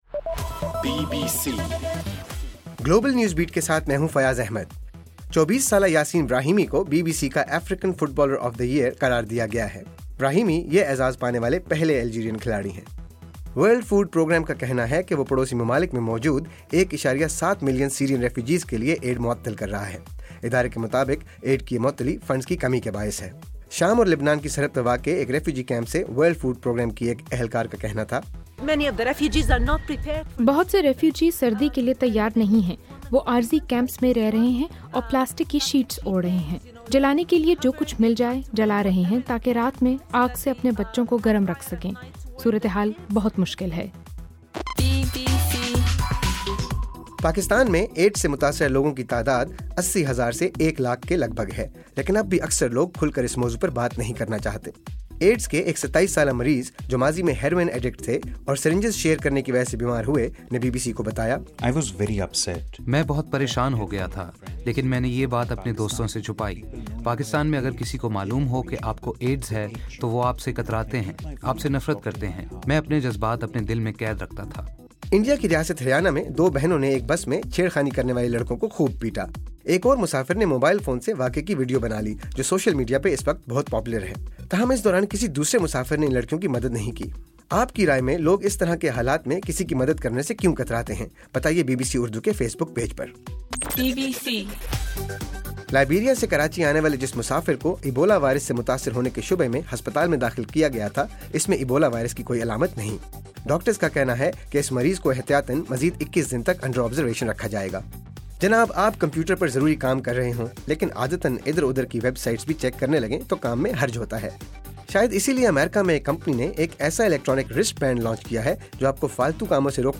دسمبر 2: صبح 1 بجے کا گلوبل نیوز بیٹ بُلیٹن